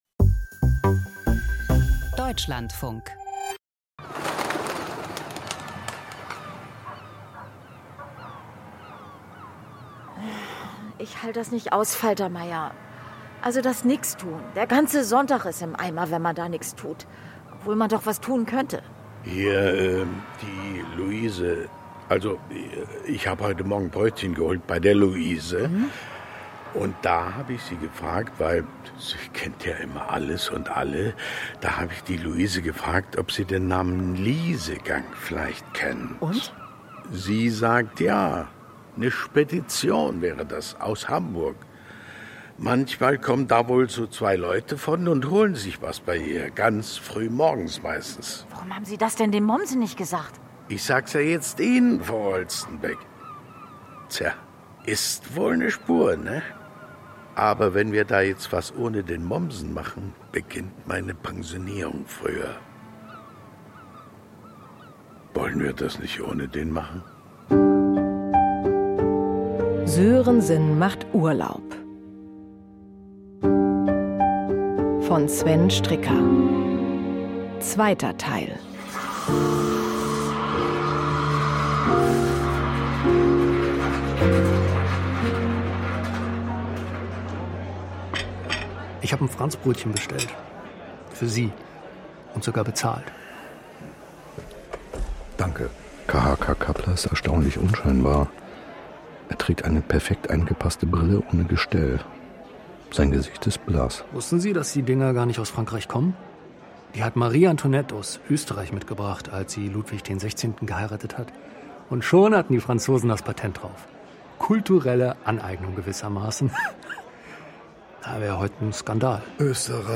Statt eine Auszeit in den Bergen zu nehmen ermittelt Kommissar Sörensen under cover in Hamburg. Krimi-Hörspiel mit Bjarne Mädel.